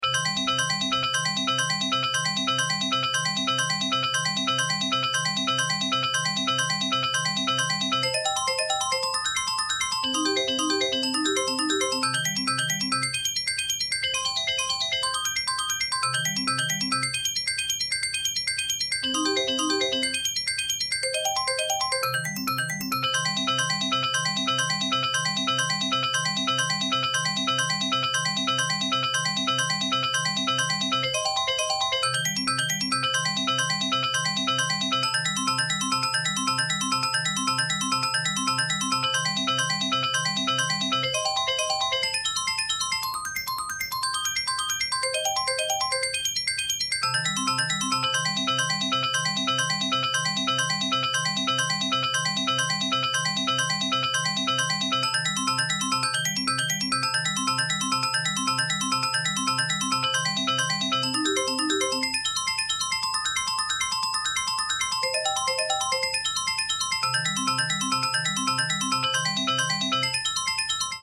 Instagram- Xilofone de metal/ 3 oitavas / track tempo:9x Youtube- Marimba/ 4 oitavas / track tempo:9x Horas- Piano/ 2 oitavas / track tempo:9x